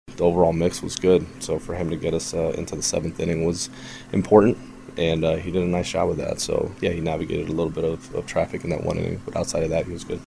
Cardinals manager Oliver Marmol talks about Matthew Liberatore’s outing.